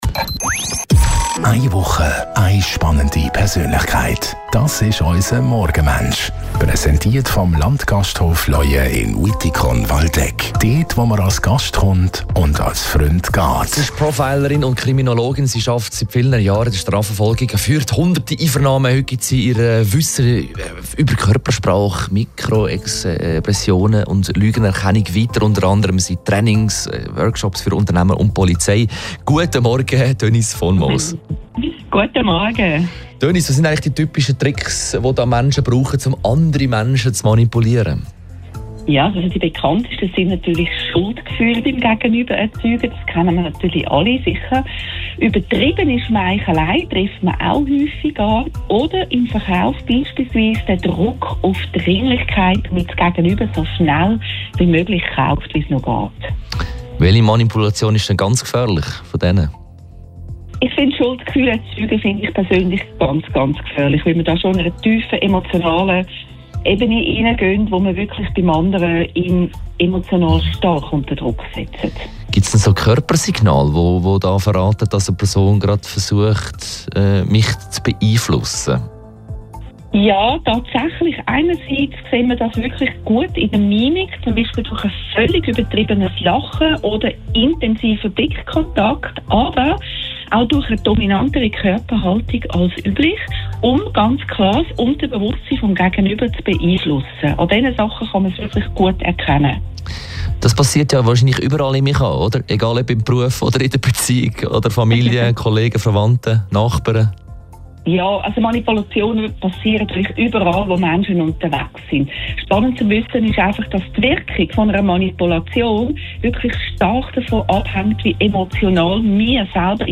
telefonieren jeden Morgen von Montag bis Freitag nach halb 8 Uhr mit einer interessanten Persönlichkeit.